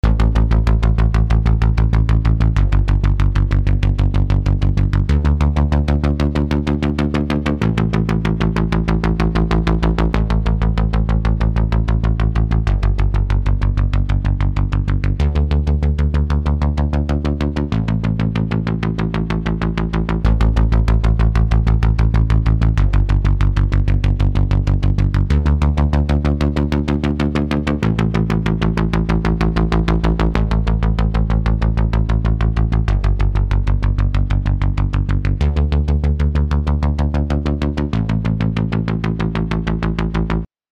Hier ein Hörbeispiel, bei dem ich eine Bassline durch die Lola geschickt habe. Erst mit Lola, dann ohne und nur mit Plug-Ins, dann beide wiederholt.
Es gibt keinen riesigen Klangunterschied, aber ohne Lola ist der Synth weniger satt im Klang und wirkt digitaler.
preamp-uebersteuert-bass-line-demo.mp3